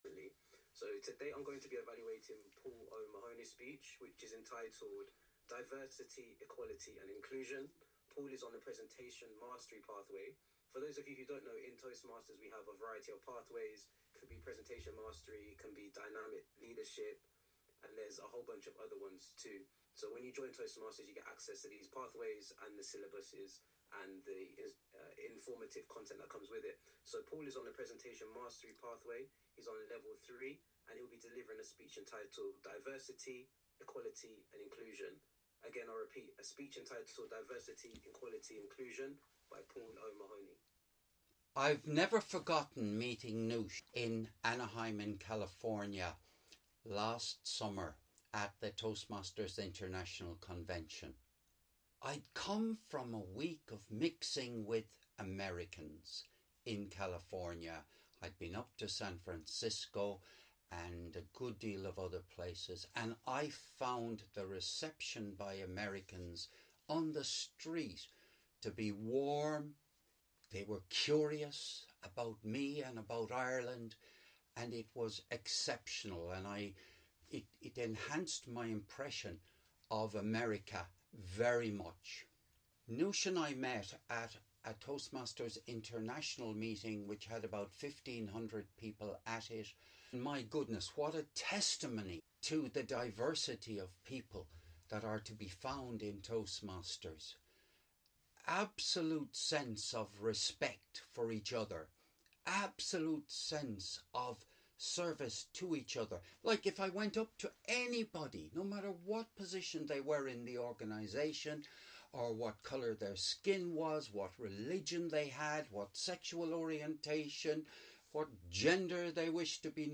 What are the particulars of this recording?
This was recorded on Sunday 23rd of March 2025 - at a meeting of the OneCountryOneWorld Toastmasters online Club